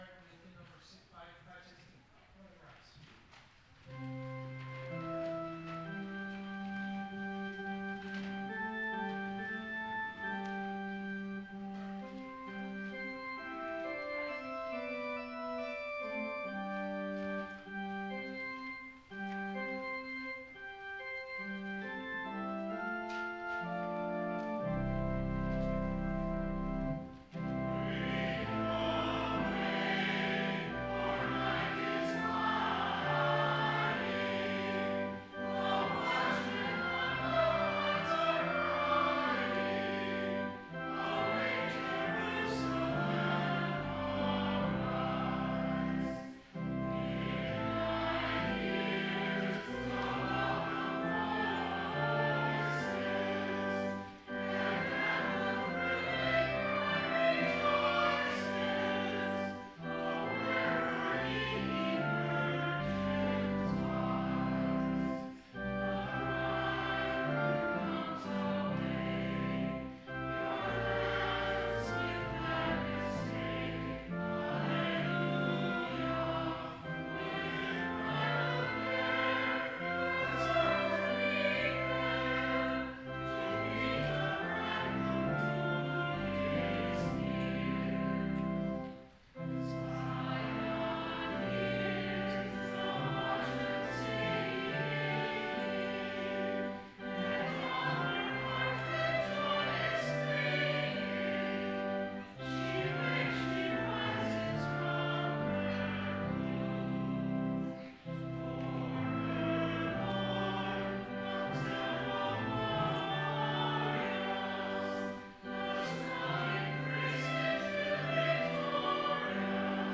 Biblical Text: Luke 3:15-22 Full Sermon Draft
These recordings can’t really capture the full service. We don’t really have the recording equipment for that, so the focus is really on the spoken parts (i.e. texts and sermon). But, I included our Choir singing a wonderful Epiphany piece.